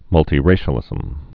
(mŭltē-rāshə-lĭzəm, -tī-)